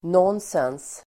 Uttal: [n'ån:sens]